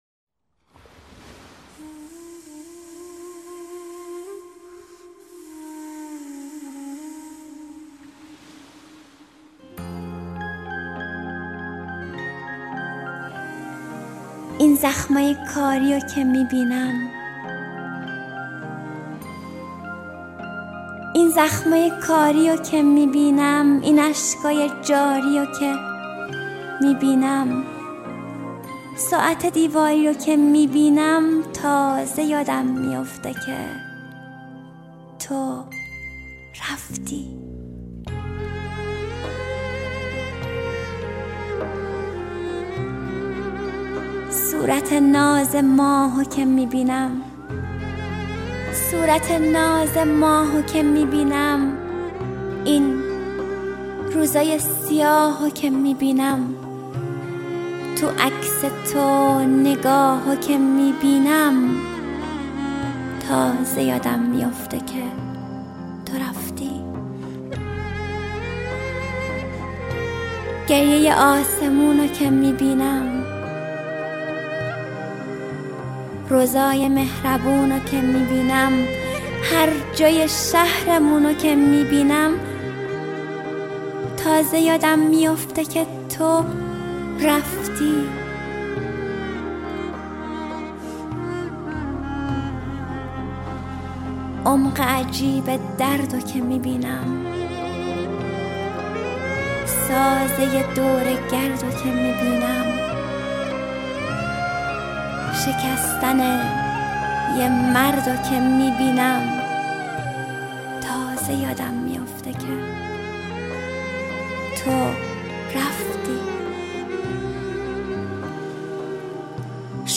دانلود دکلمه تاره یادم میوفته که تو رفتی با صدای مریم حیدرزاده
گوینده :   [مریم حیدرزاده]